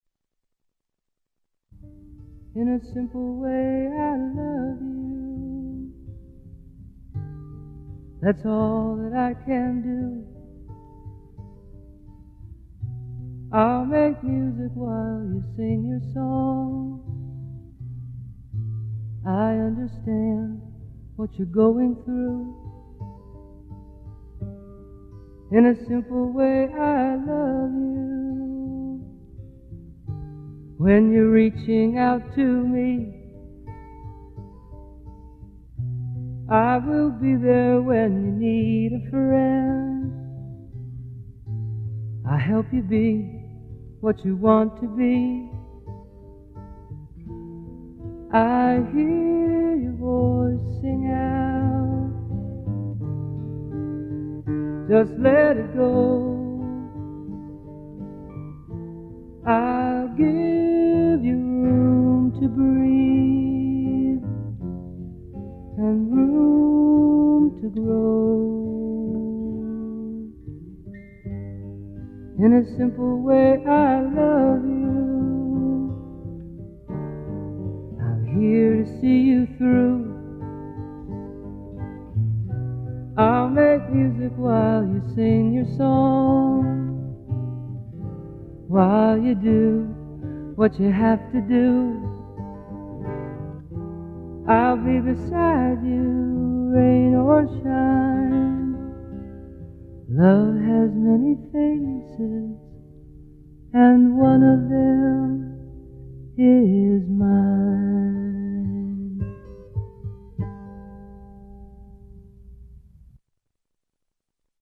这首歌没有华丽的配乐，简单得只有一把旧吉它，而且只是用普通的录音机录下，背景中隐约有很多滋滋声。
在场的人听着这首歌，很多被歌者温柔的声音所感动。
之后又经过几次录音机的翻录，才最后转到电脑上。